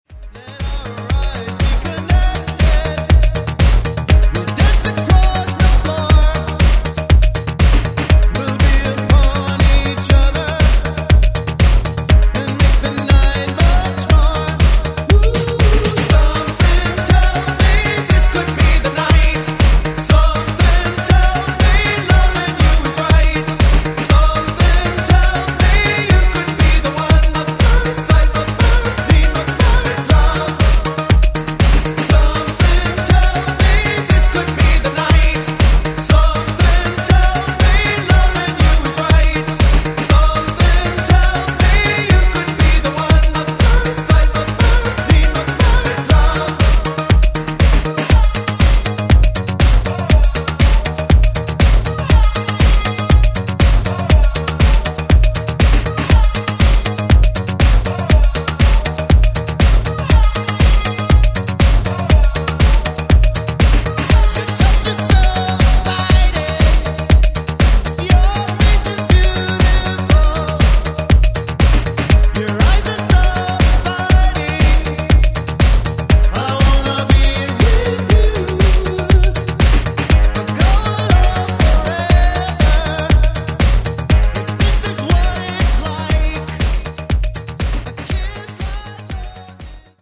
Genre: 70's Version: Clean BPM: 128 Time